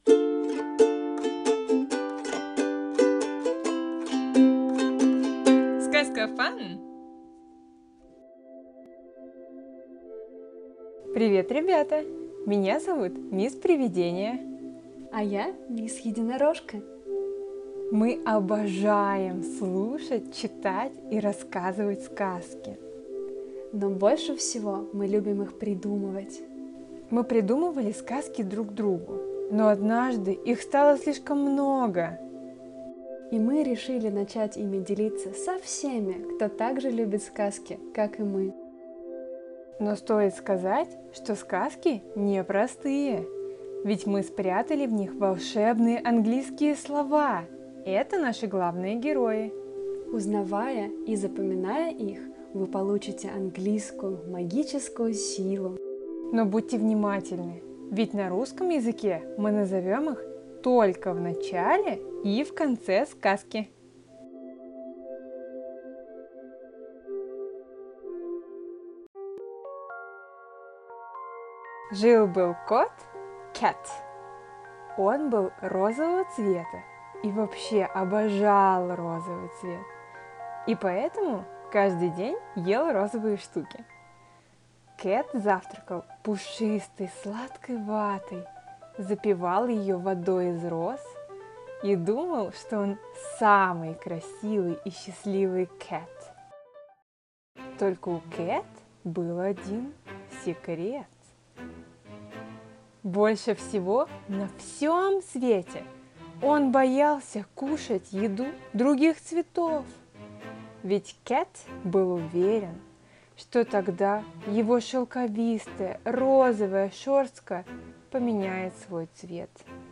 CAT и DOG на Aнанасовом Холме - аудиосказка для малышей